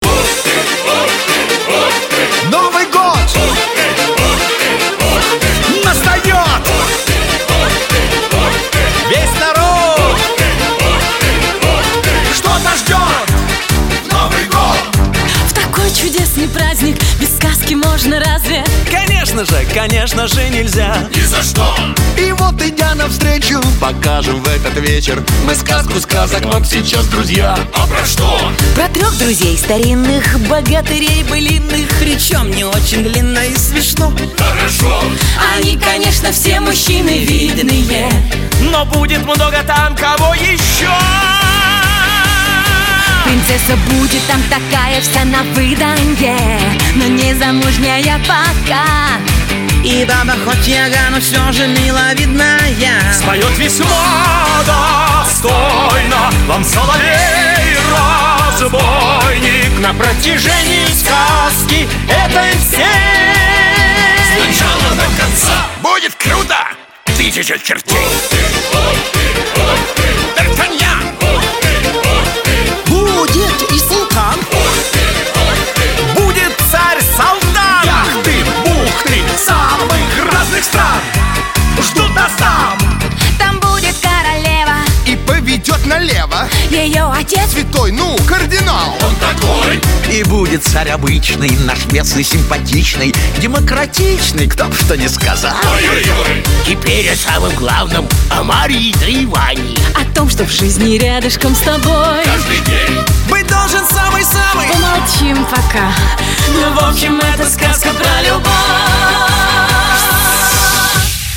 Небольшой концерт - песни из музыкальной сказки Три богатыря...
Вступительная песня.mp3